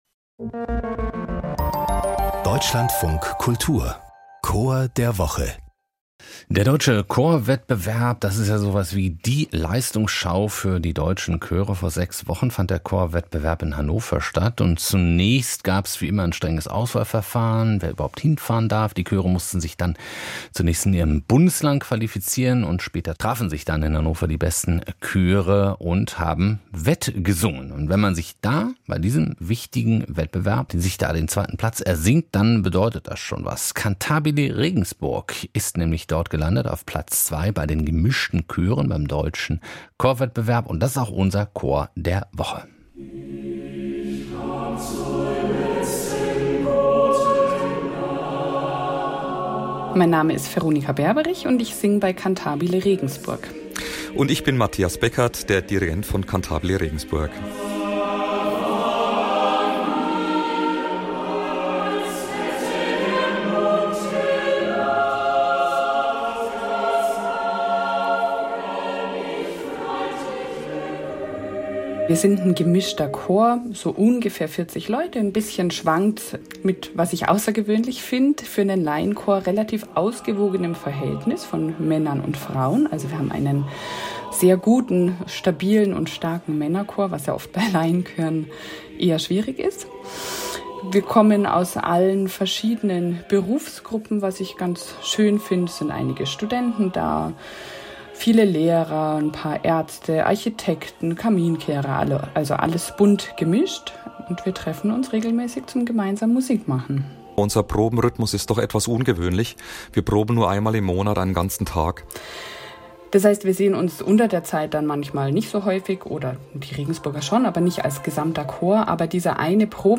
Hier stellen wir Ihnen jede Woche einen Chor vor.